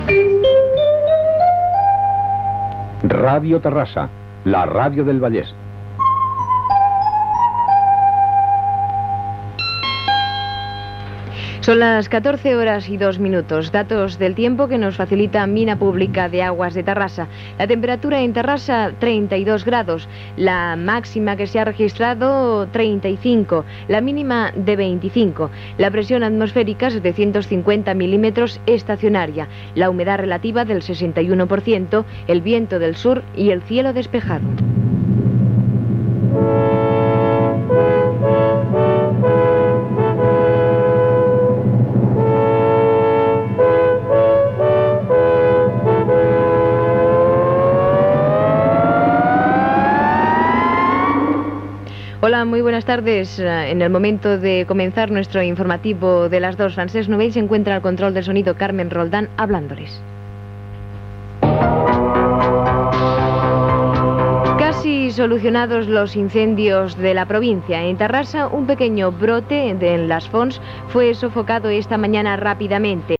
Indicatiu de l'emissora, hora, informació del temps, careta de l'informatiu, incendis a Terrassa.
Informatiu